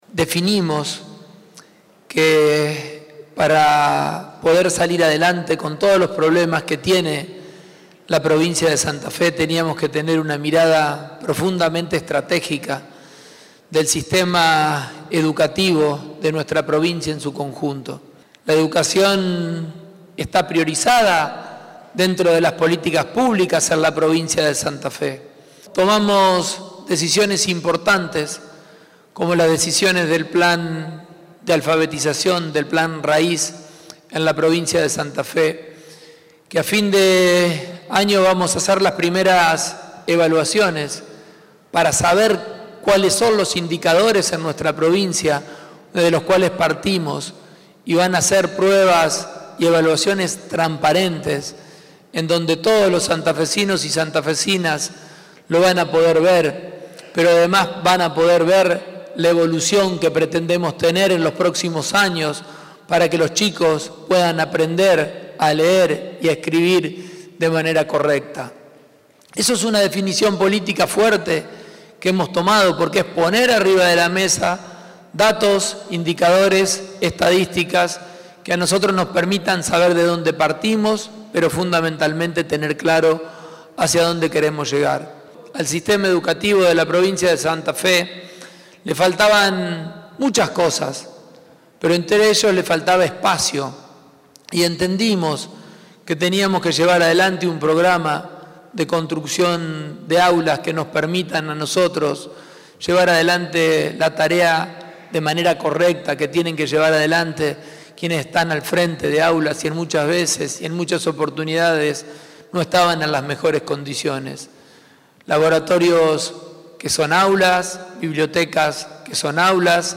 En el acto, que se desarrolló en el Salón Blanco de la Casa de Gobierno, se rubricaron acuerdos para construir 75 nuevas aulas en establecimientos educativos santafesinos, que alcanzan a 63 localidades.